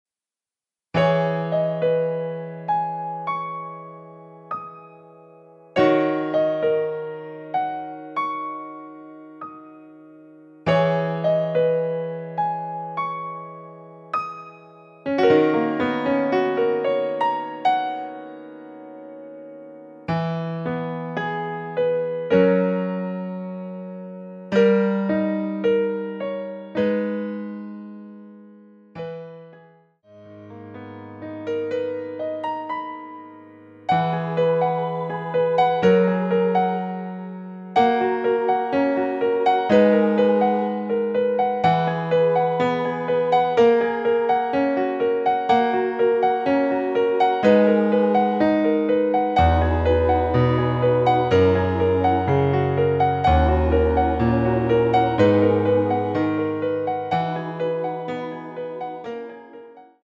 Abm
음정은 반음정씩 변하게 되며 노래방도 마찬가지로 반음정씩 변하게 됩니다.
앞부분30초, 뒷부분30초씩 편집해서 올려 드리고 있습니다.
원곡의 보컬 목소리를 MR에 약하게 넣어서 제작한 MR이며
노래 부르 시는 분의 목소리가 크게 들리며 원곡의 목소리는 코러스 처럼 약하게 들리게 됩니다.